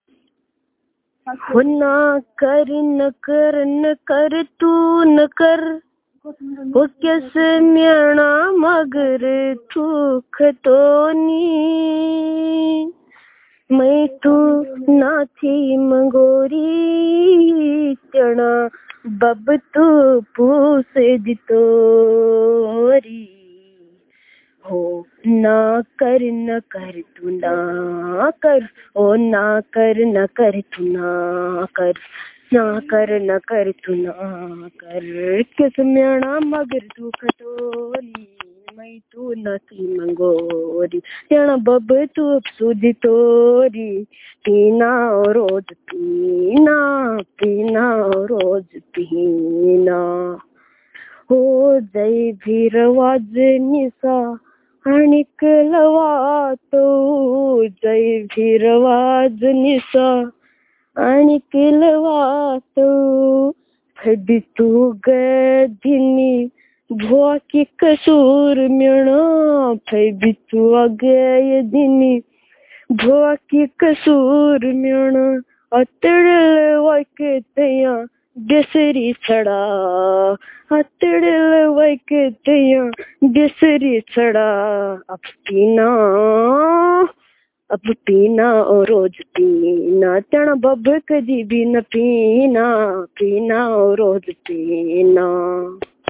Performance of folk songs